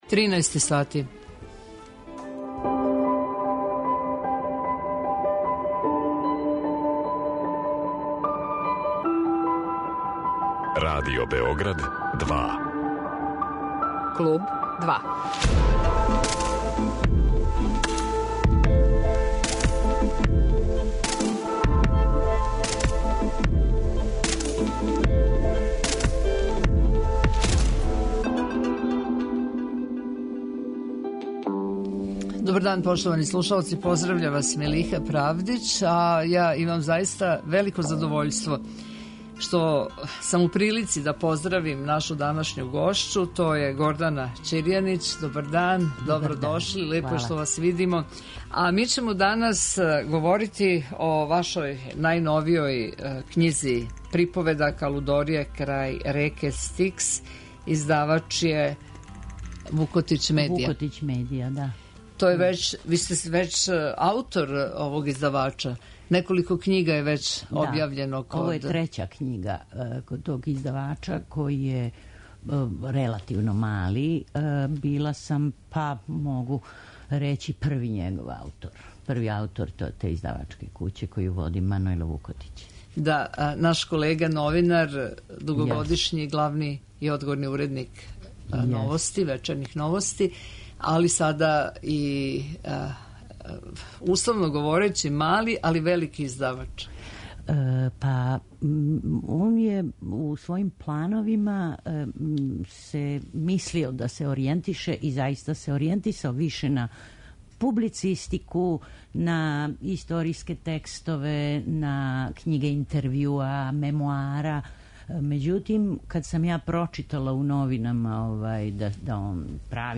Гошћа 'Клуба 2' је књижевница Гордана Ћирјанић, а говоримо о њеној књизи 'Лудорије крај реке Стикс' у издању 'Вукотић медиа д.о.о.'